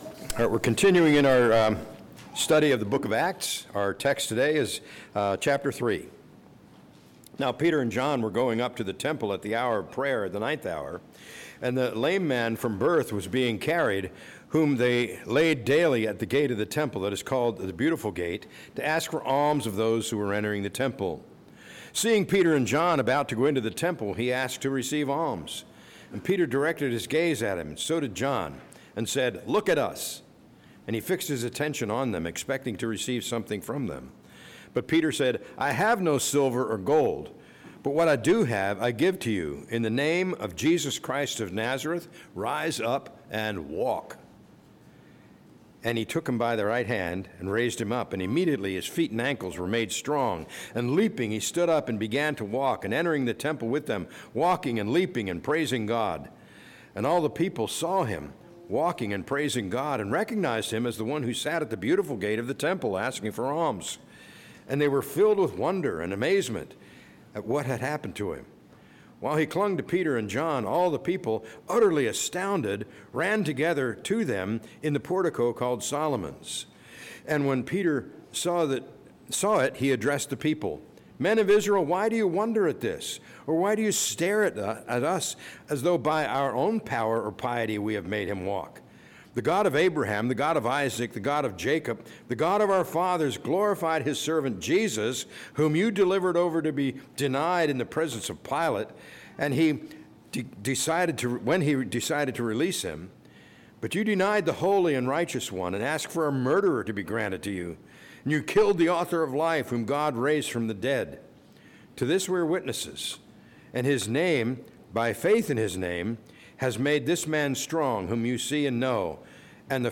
A message from the series "Acts 2025/26."